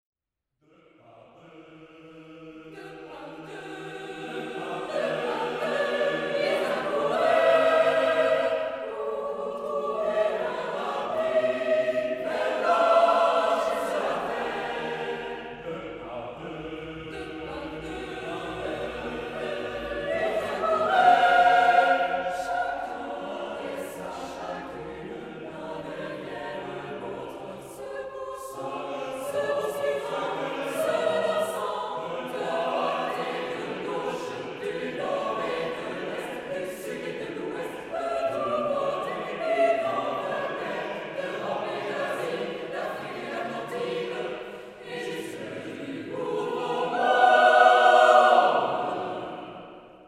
Retrouvez ici des extraits « live » de nos concerts !
Florent Schmitt – L’Arche de Noé – Église Notre-Dame-du-Liban
florent-schmitt-larche-de-noe-calligrammes-2017-concert-nd-des-billettes-mp3.mp3